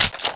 assets/pc/nzp/sounds/weapons/m1carbine/magout.wav at 180cc041c83f7f6b565d5d8fbf93c3f01e73f6a5